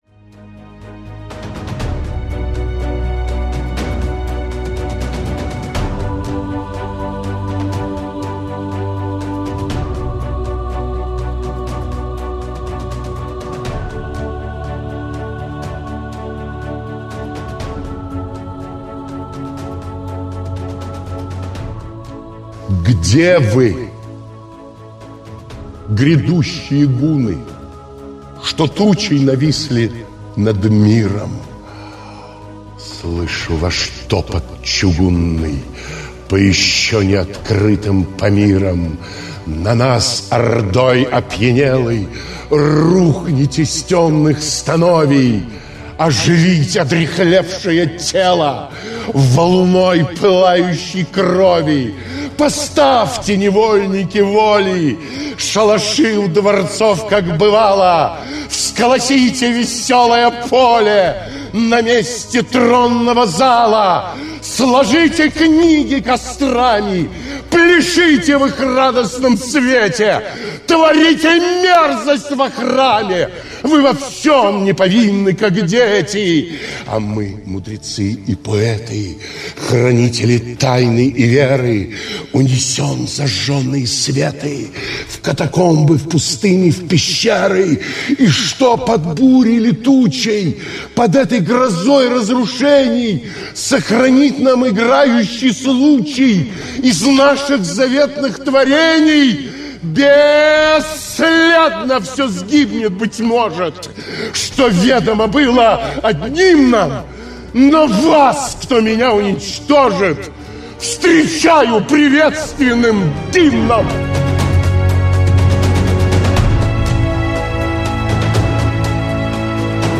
2. «СТИХОрама № 2083. Валерий Брюсов – Грядущие гунны (чит. Дмитрий Назаров)» /